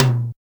626 TOM1 MD.wav